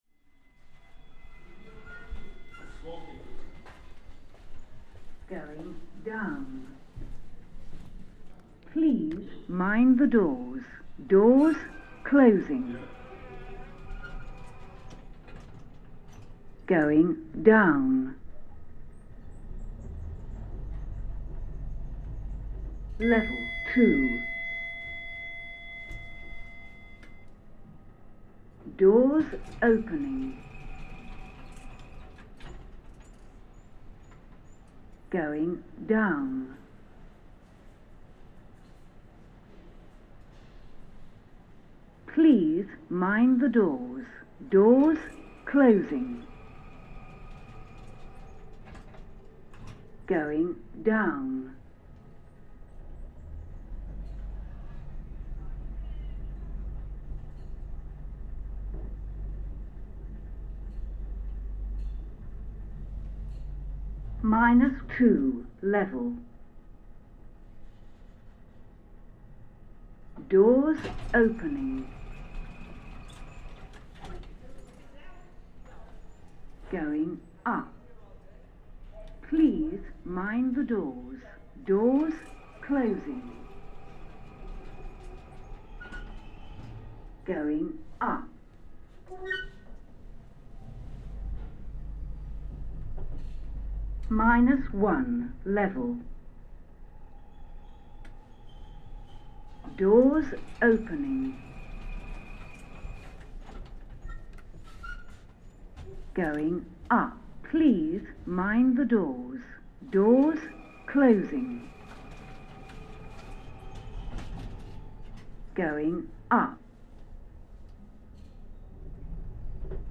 Doors opening...
In this recording, we ride the lifts inside the Barbican Centre, taking a close listen to the announcements, closing and opening doors and lift mechanisms inside this brutalist wonder. At the end of the recording we emerge into a very busy cocktail bar, to the sounds of early evening merriment.